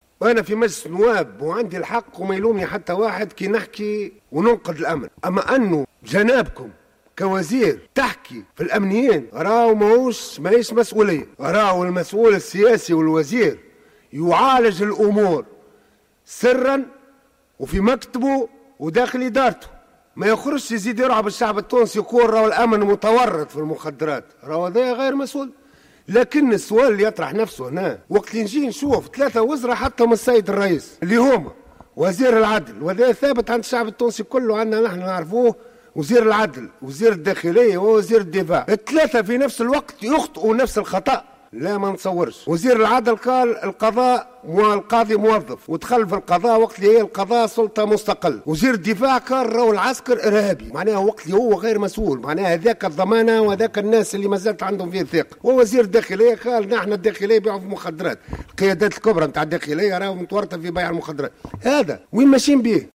عبر النائب عن كتلة حركة النهضة بشر الشابي في مداخلة له في الجلسة المخصصة لمناقشة ميزانية وزارة الداخلية اليوم الإثنين عن إدانته لما وصفها بالتصريحات غير المسؤولة لوزير الداخلية بتورط كوادر أمنية في تهريب المخدرات.